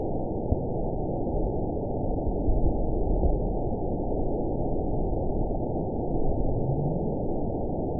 event 919964 date 01/31/24 time 09:47:06 GMT (1 year, 4 months ago) score 9.63 location TSS-AB08 detected by nrw target species NRW annotations +NRW Spectrogram: Frequency (kHz) vs. Time (s) audio not available .wav